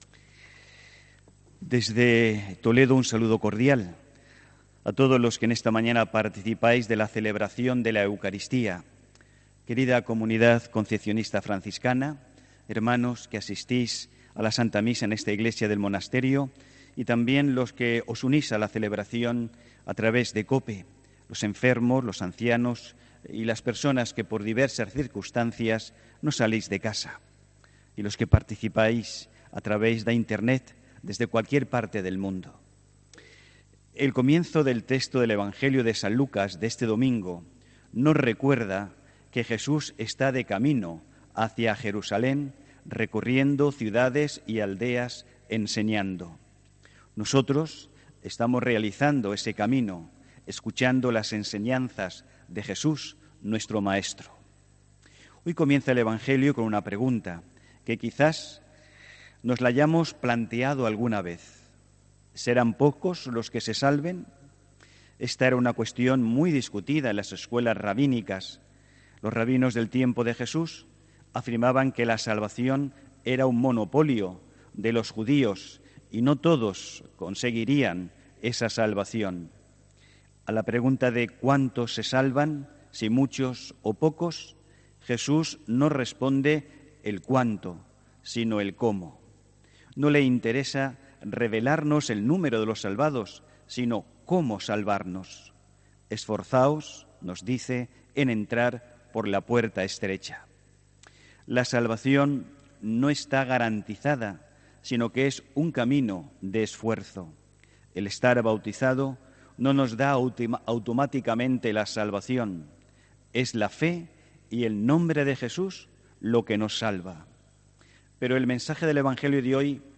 Homilía